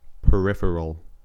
Ääntäminen
IPA : /pəˈɹɪf(ə)r(ə)l/